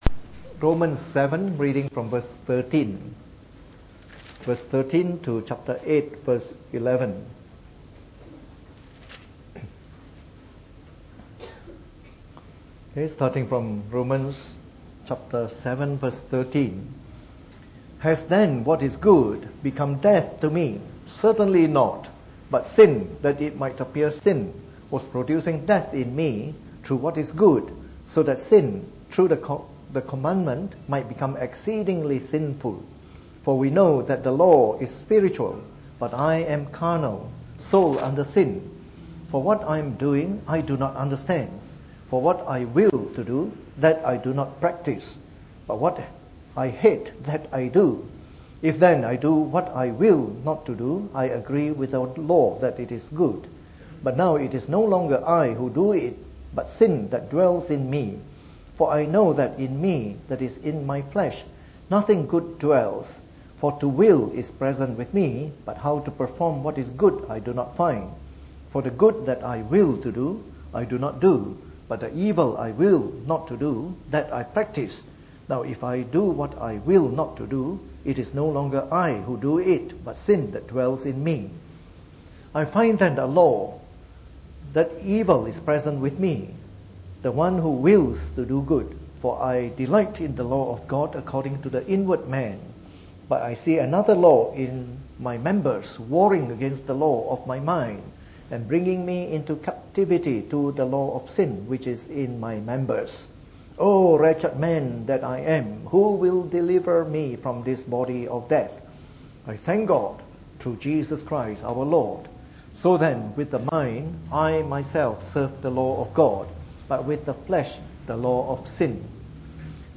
Preached on the 4th of February 2015 during the Bible Study, from our series of talks on Sanctification.